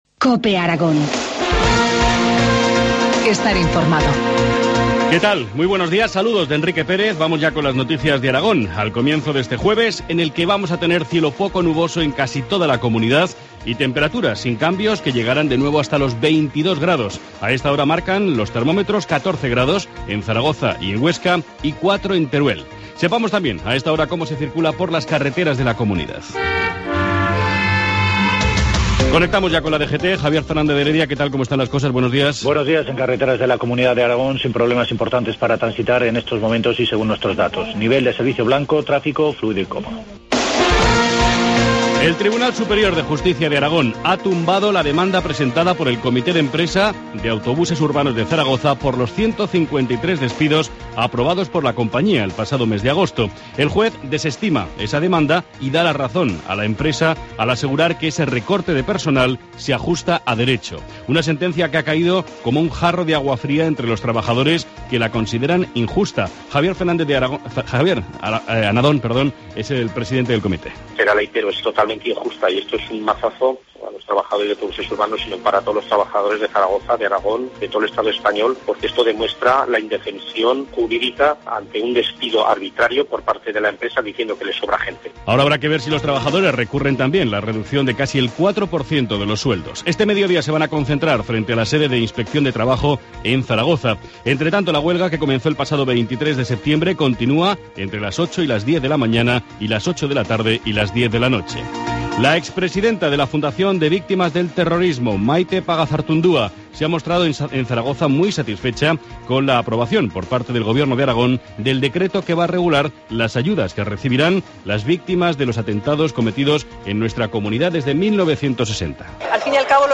Informativo matinal, jueevs 7 de noviembre, 7.25 horas